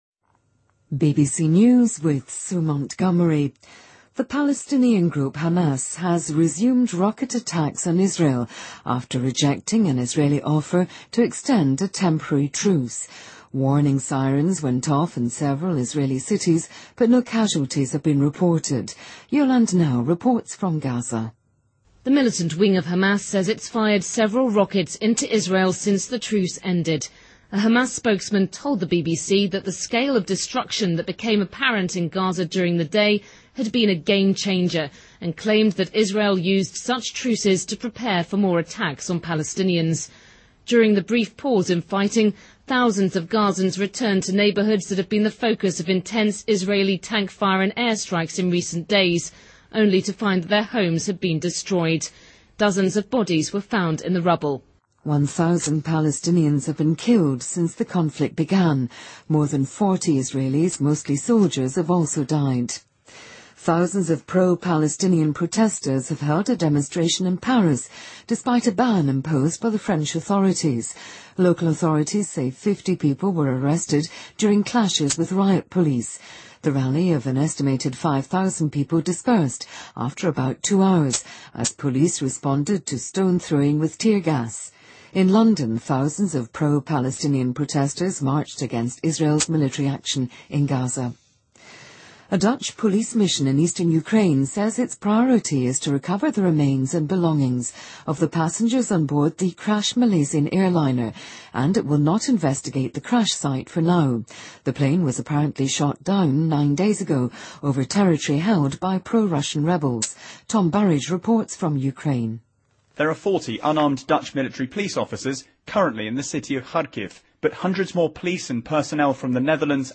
BBC news,中国广东省800多名裸官被调整